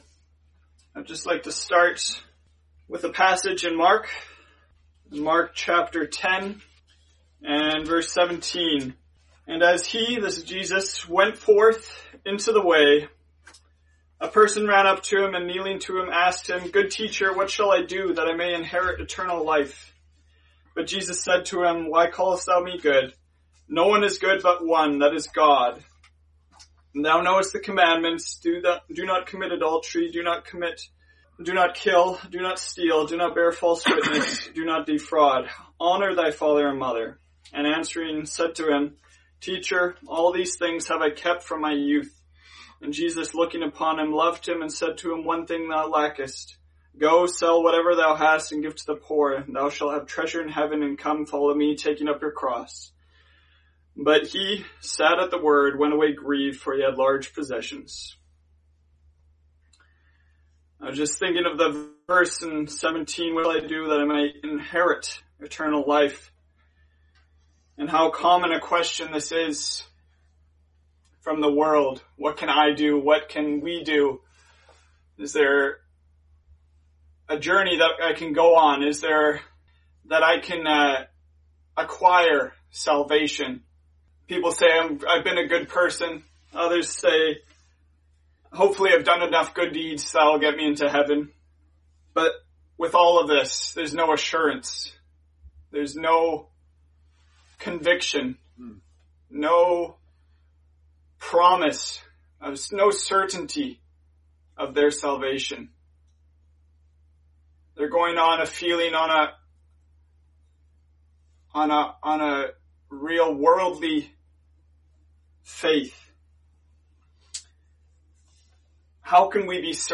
Many people today are looking for assurance in their money, possessions or career. The Bible tells us the only way to receive assurance is by accepting the Lord Jesus Christ as your Lord and Saviour. Listen to this Gospel preaching and discover what Jesus has done to save you from your sins and give you assurance of salvation.